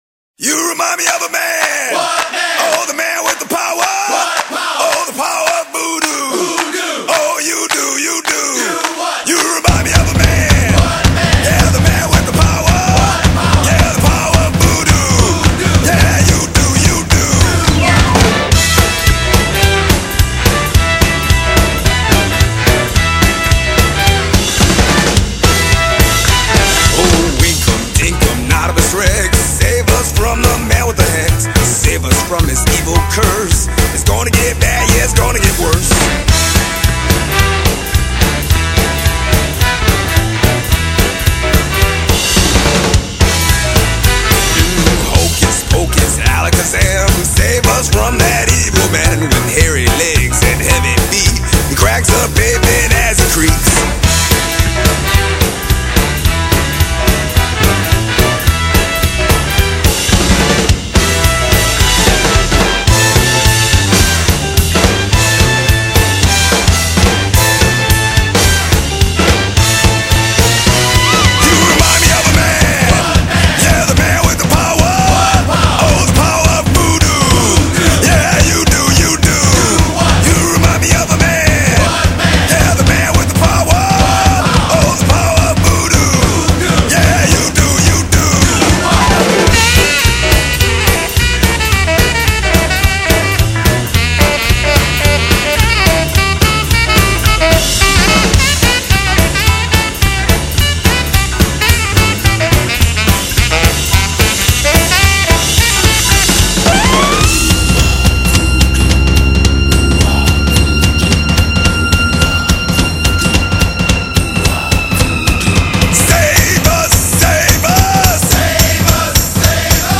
Классная безбашенная...